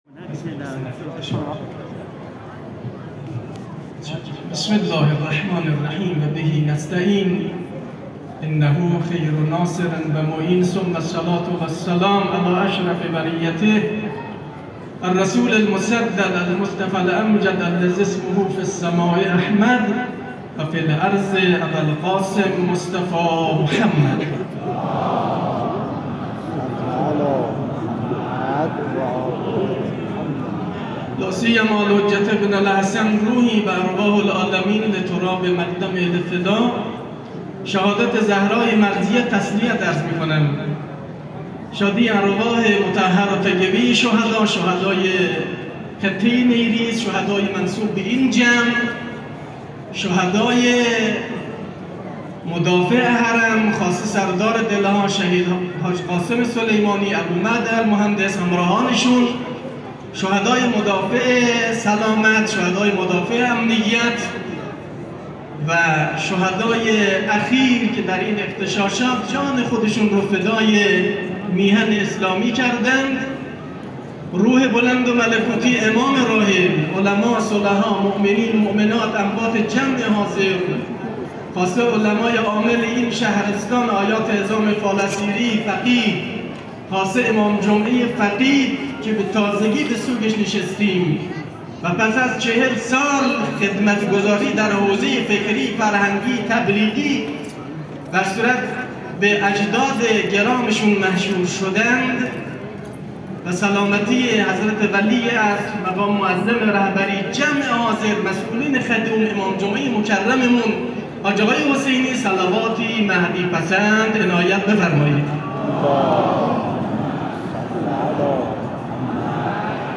سخنرانی روز شهادت حضرت زهرا سلام الله علیها.aac